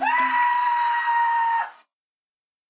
Play, download and share TravScream original sound button!!!!
travscream_HWlKI4t.mp3